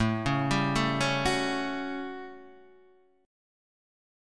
A13 chord